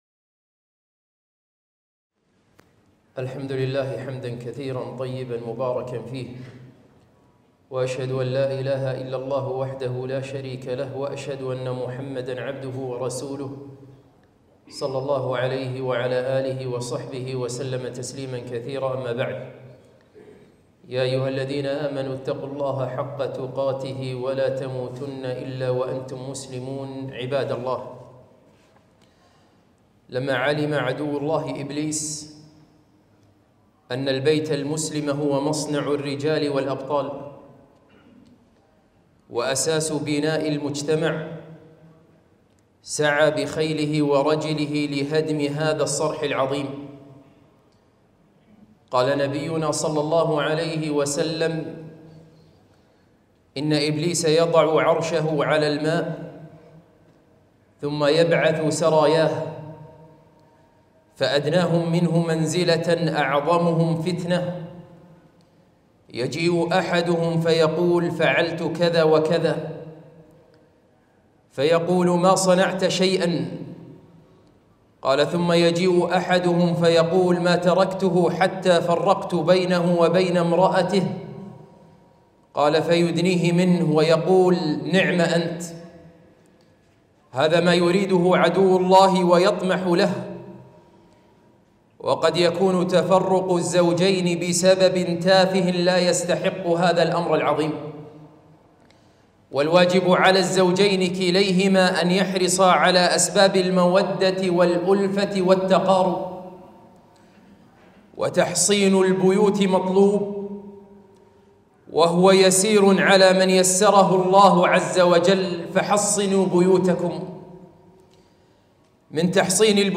خطبة - حصنوا بيوتكم